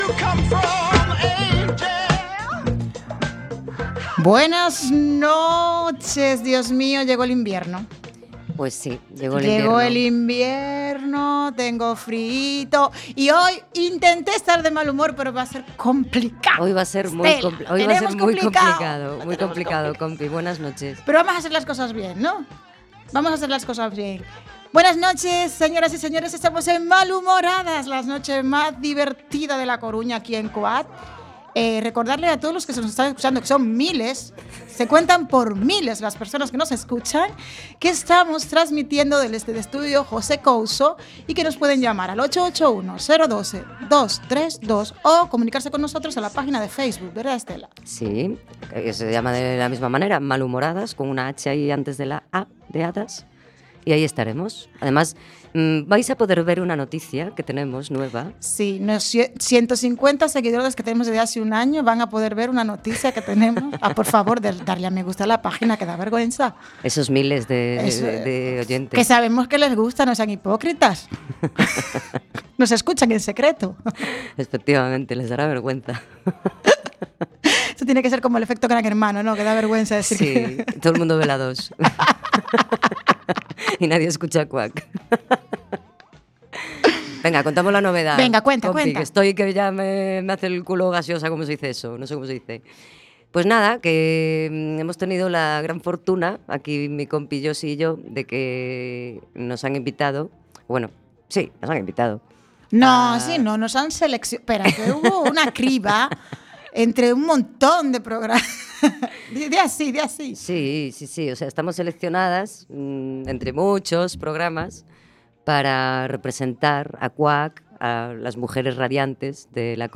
Somos un par de malhumorhadas que, en cada programa, creceremos en número y en espíritu, acompañándonos de personas que, como nosotras, se pre-ocupan y se ocupan de las cosas importantes, dando apoyo a proyectos y movimientos sociales con diferentes causas y objetivos. Todo esto con mucho humor (bueno y malo) y con mucha música y diversión, todos los miércoles a las 20:00 en Cuac FM (redifusión: sábados 10:00) y los jueves a las 14:00 en OMC Radio.